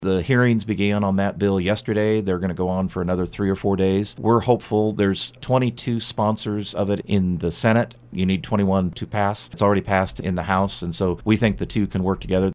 Kansas Lt. Gov. Lynn Rogers stopped by KMAN this morning to discuss various items proposed in Gov. Laura Kelly’s State of the State address and 2020 budget proposal.